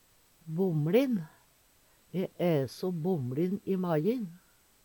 Høyr på uttala Ordklasse: Adjektiv Attende til søk